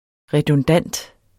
Udtale [ ʁεdɔnˈdand ]